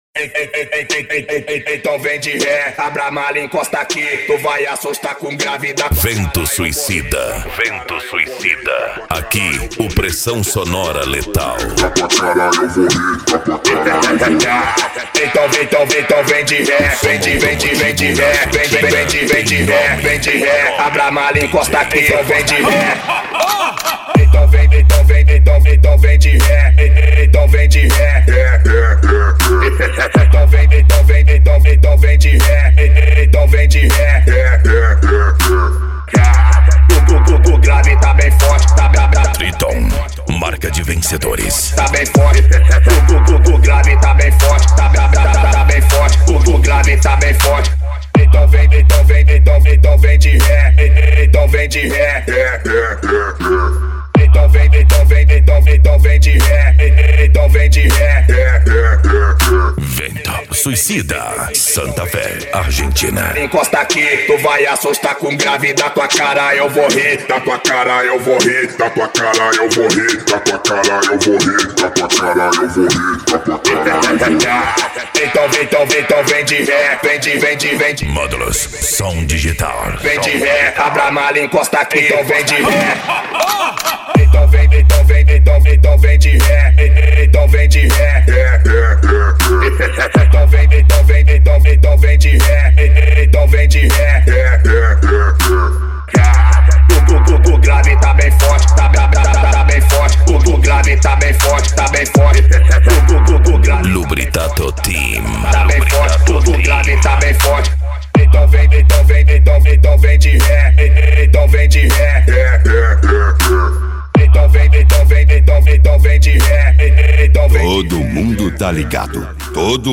Bass
PANCADÃO
Psy Trance
Racha De Som